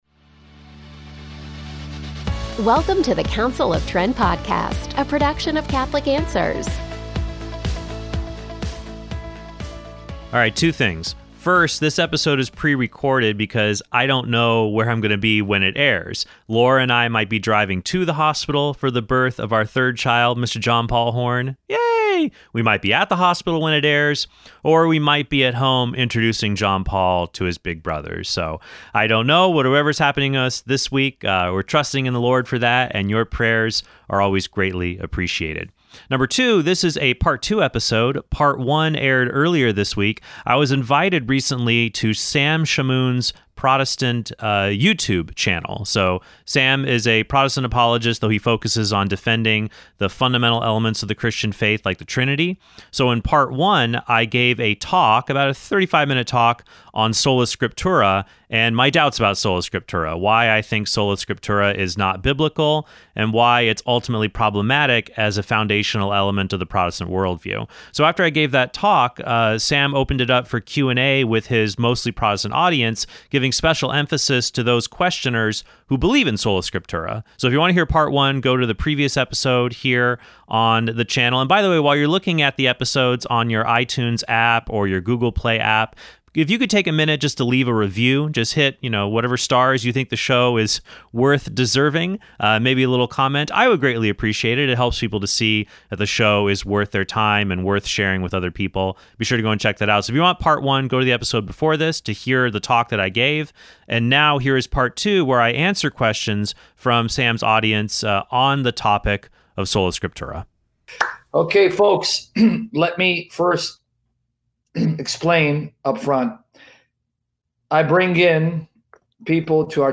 First, this episode is pre-recorded because I don’t know where I’m going to be when it airs.